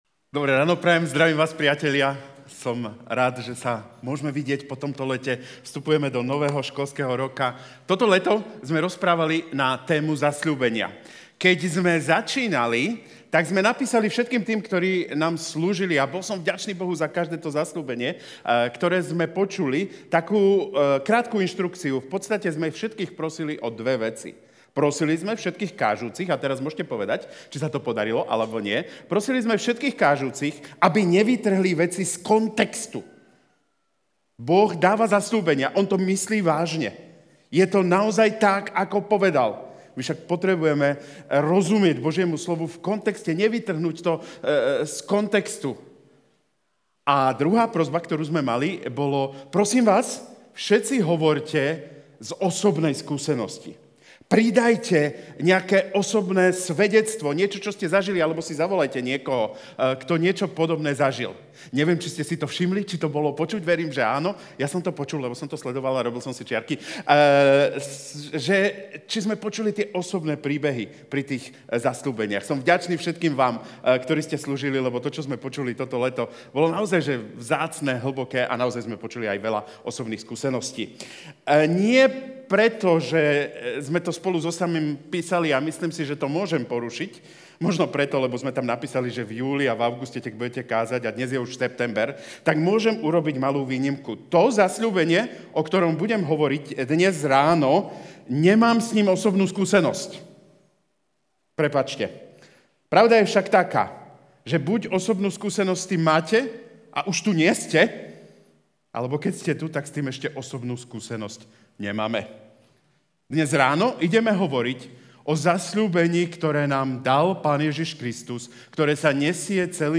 Kázeň týždňa